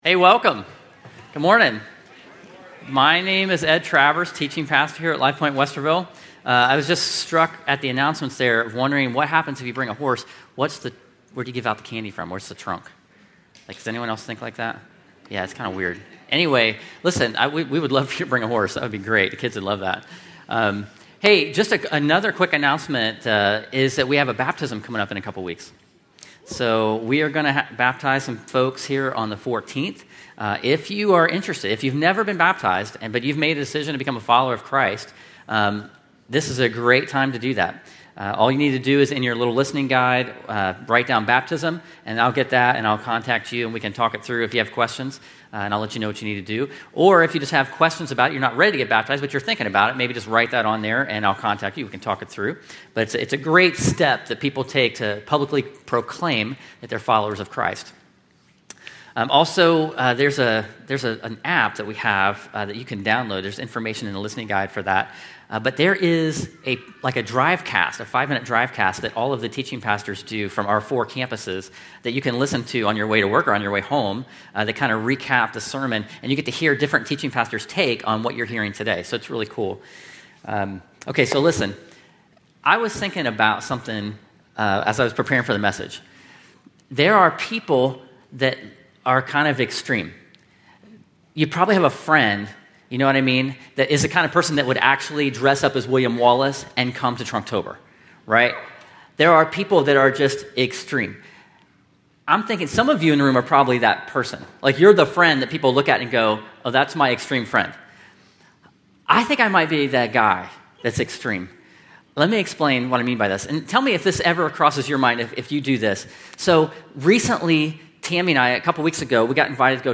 “Extreme Measures” where we learn that God goes to extreme measures to free His people. This sermon is part of the series “Into the Wild.” The sermon was given at Lifepoint Westerville. The new Series “Into the Wild” covers the book of Exodus.